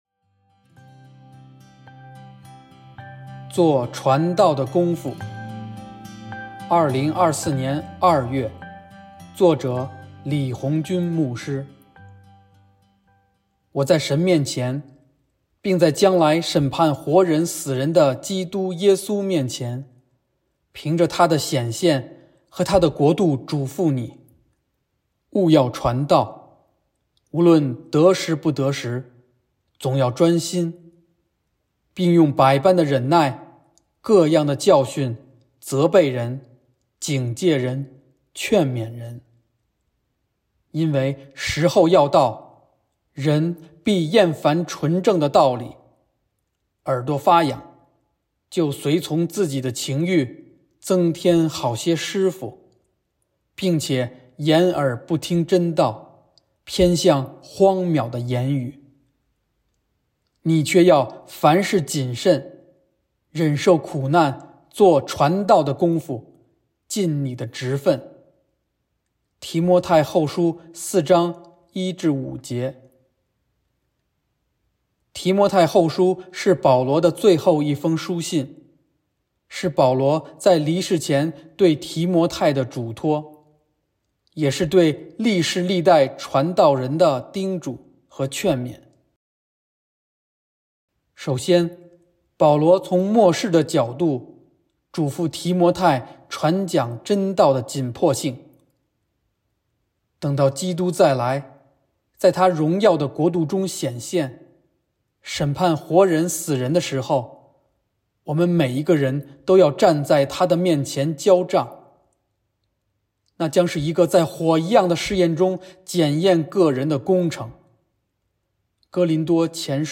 音频朗读